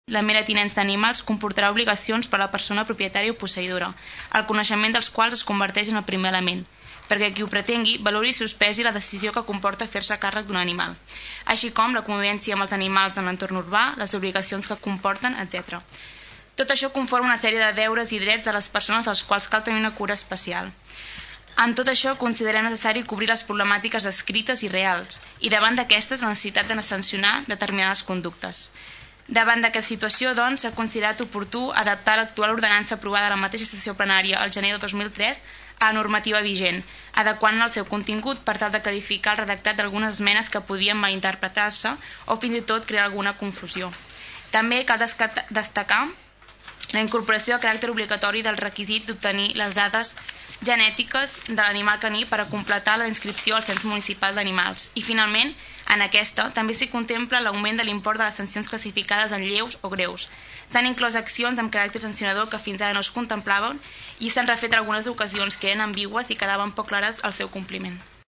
Escoltem la regidora de civisme, Bàrbara Vergés.